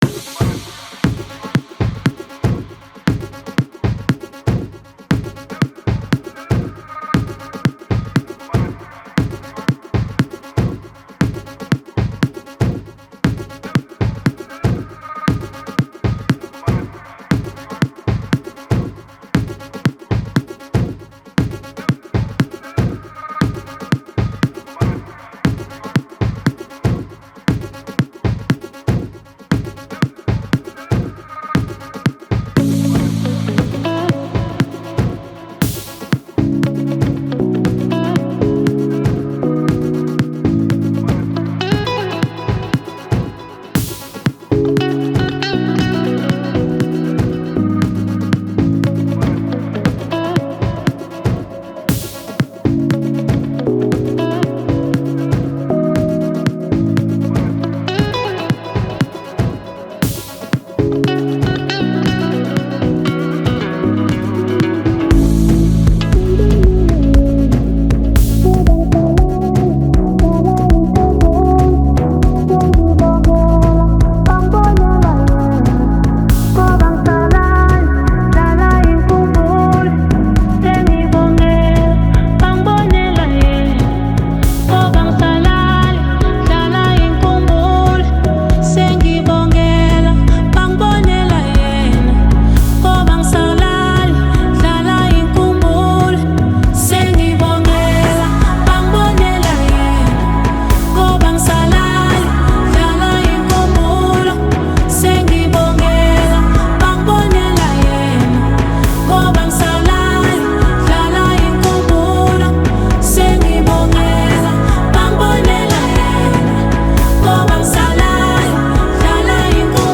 #3step